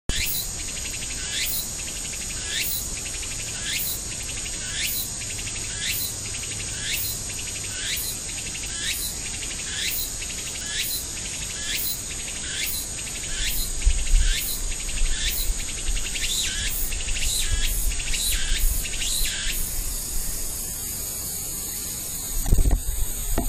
季語散策22 法師蝉
鳴き声がツクツクホーシツクツクホーシと聞こえる。
tukutukusi.mp3